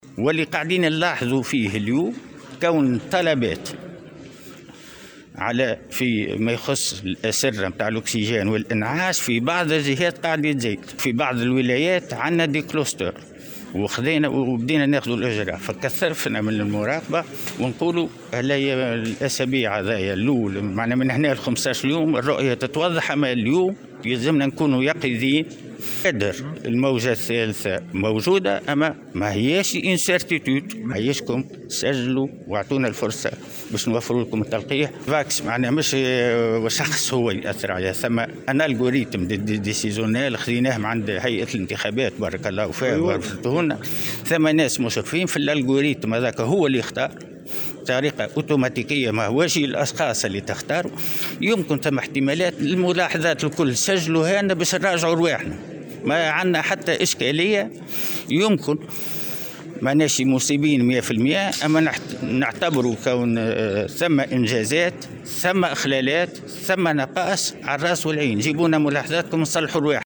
وأضاف في تصريح اليوم لمراسل "الجوهرة أف أم" على هامش مشاركته في ندوة لتقديم المدينة الصحية ببلدية تونس، أن الإخلالات والنقائص موجودة وأن الوزارة تعمل على تفاديها.